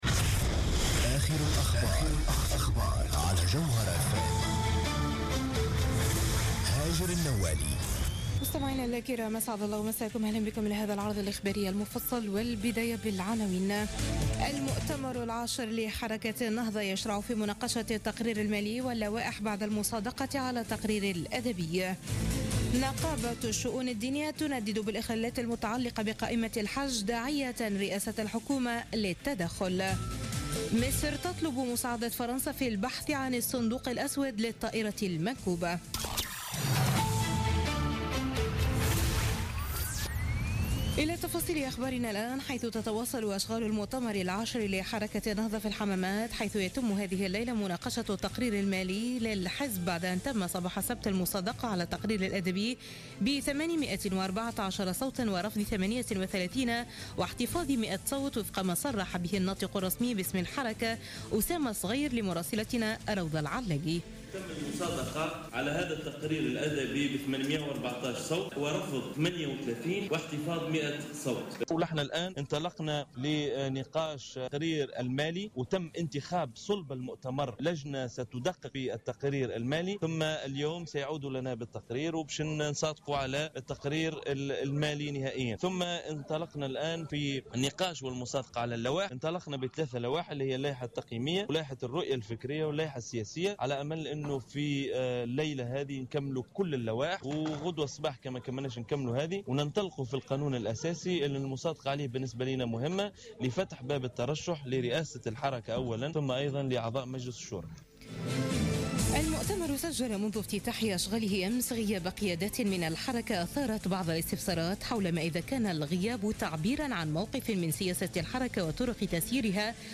Journal Info 00h00 du dimanche 22 mai 2016